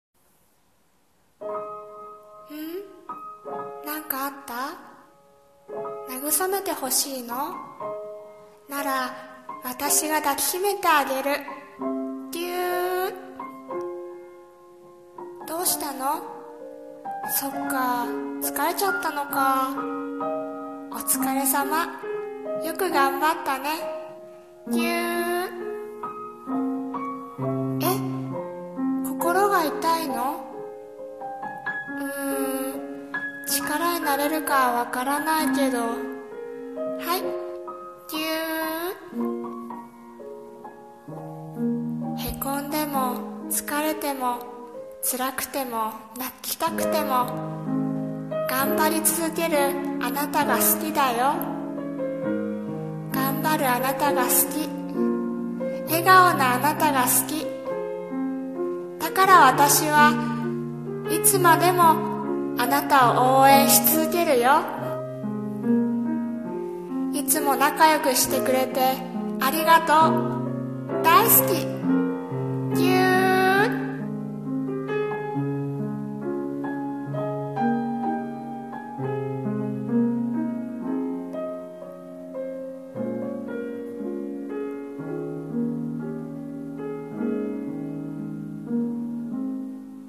さんの投稿した曲一覧 を表示 【一人声劇】ぎゅー。